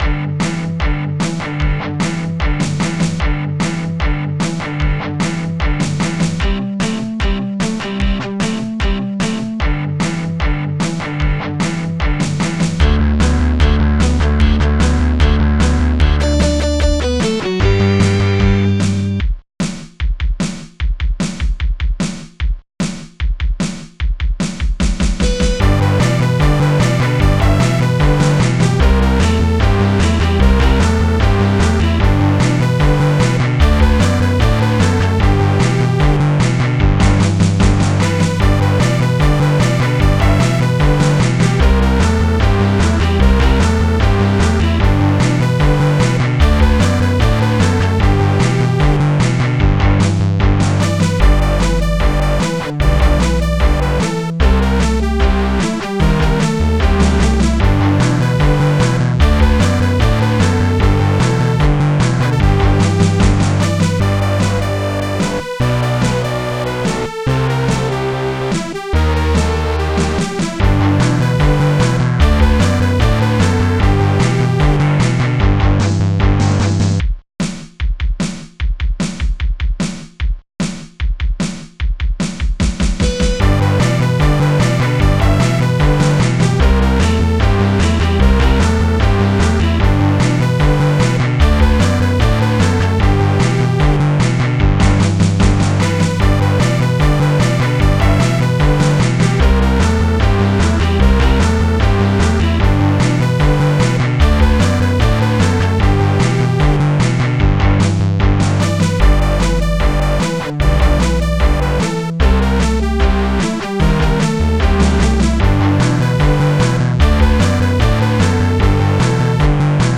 ROCK-2019.mp3